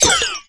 lou_dryfire_01.ogg